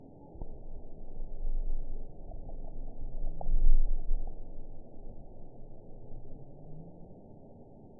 target species NRW